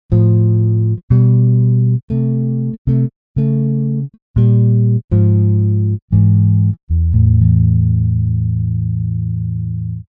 Вложения Bass line cut.mp3 Bass line cut.mp3 397,4 KB · Просмотры: 762